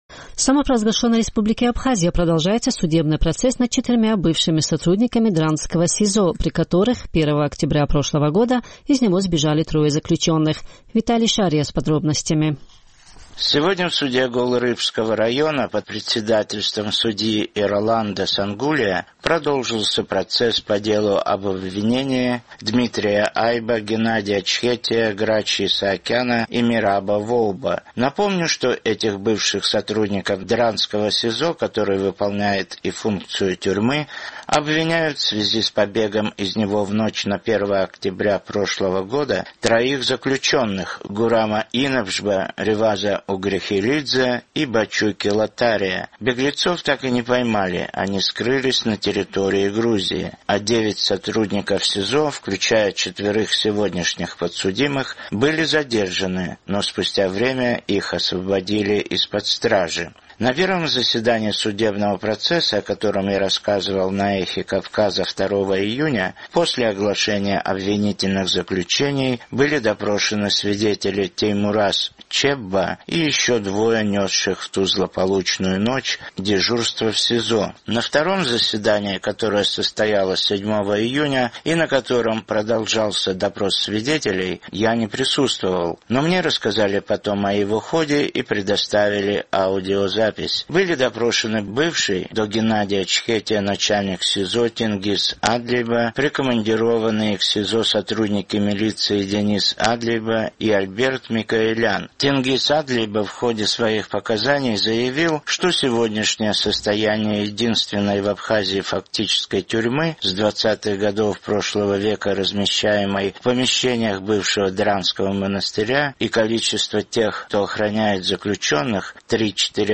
На втором заседании, которое состоялось 7 июня и на котором продолжался допрос свидетелей, я не присутствовал, но мне рассказали потом о его ходе и предоставили аудиозапись.